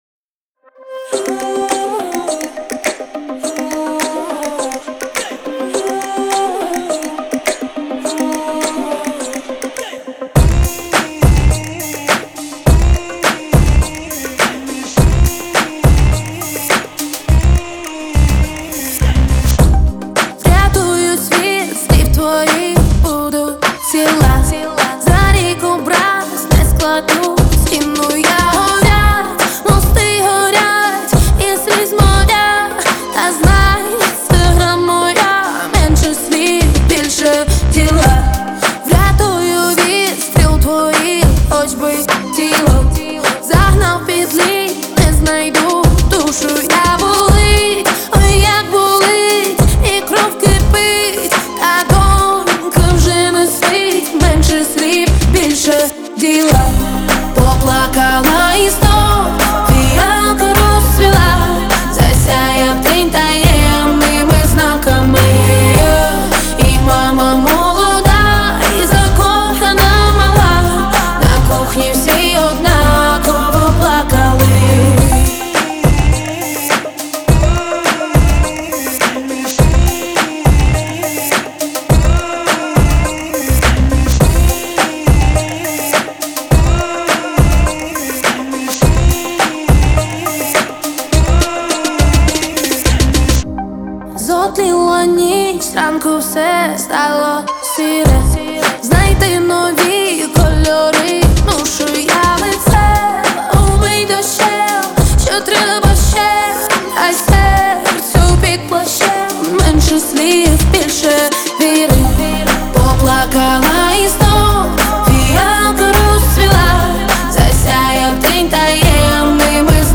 PopПопулярная музыка